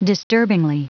Prononciation du mot disturbingly en anglais (fichier audio)